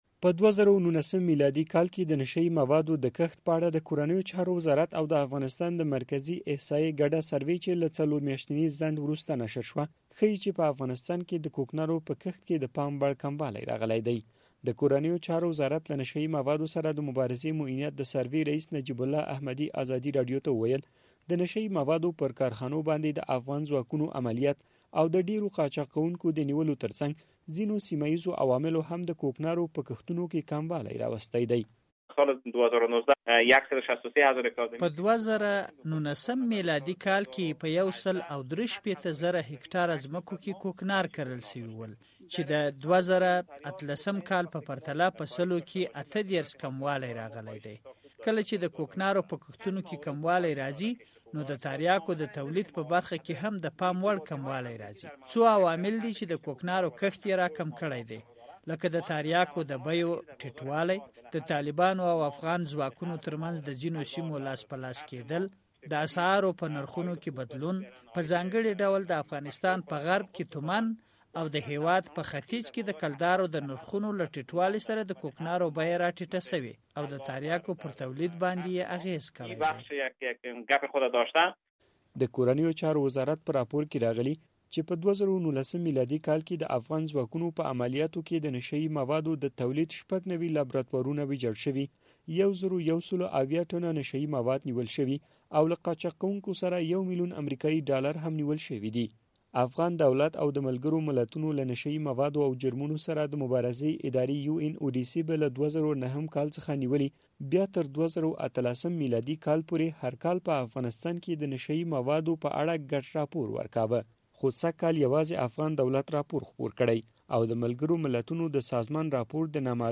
د کوکنارو کښت په اړه راپور